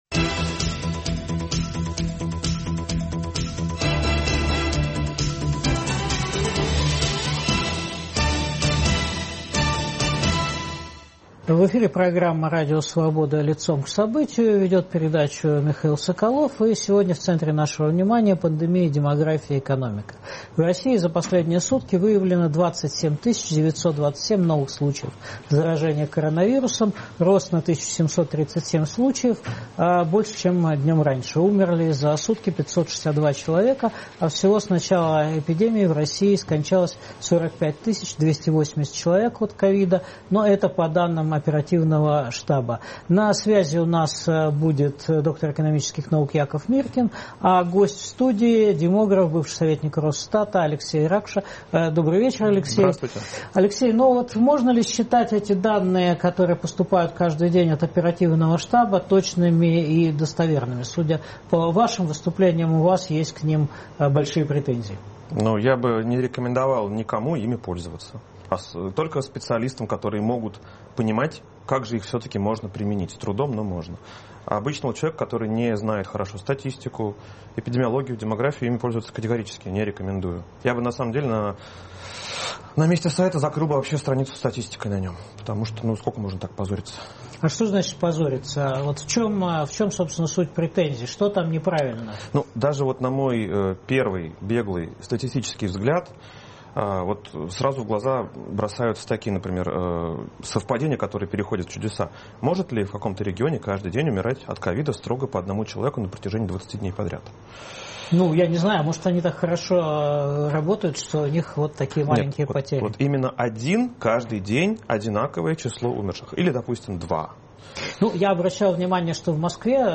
Кремль пожертвует пожилыми во время второй волны эпидемии? Альтернативу обсуждают экономист